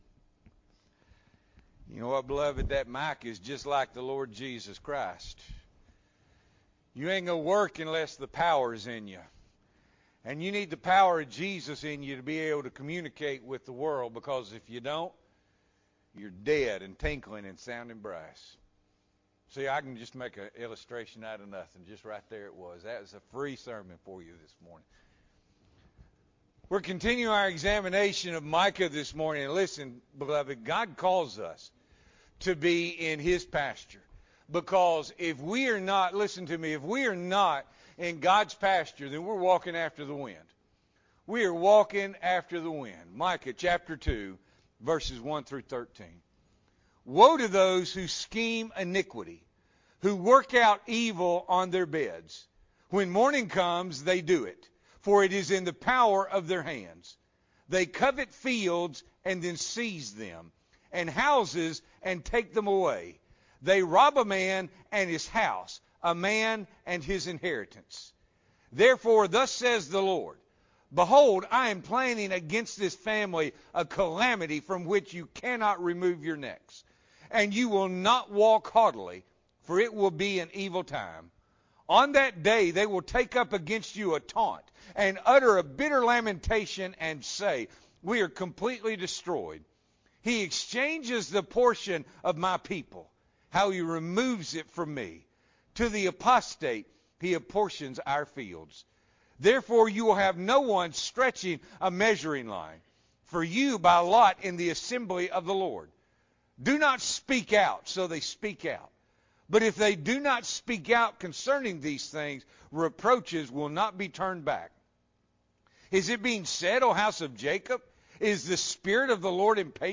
May 2, 2021 – Morning Worship